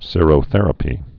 (sîrō-thĕrə-pē)